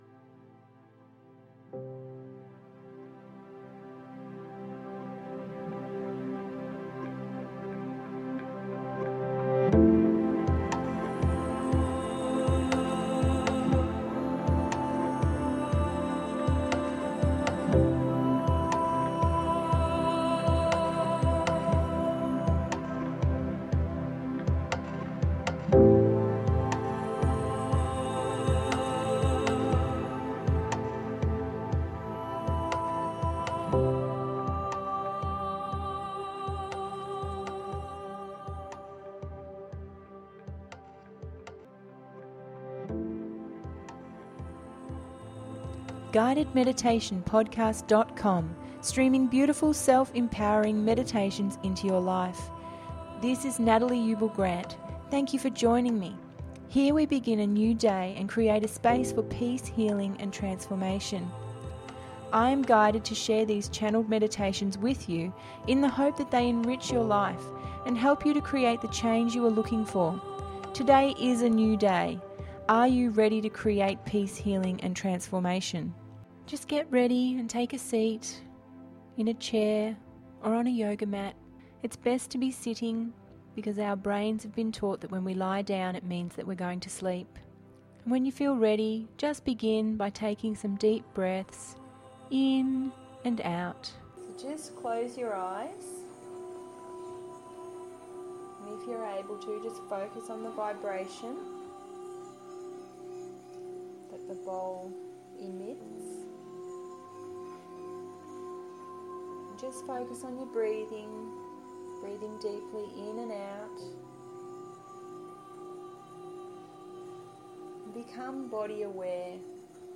Crystal Deva Pyramid…054 – GUIDED MEDITATION PODCAST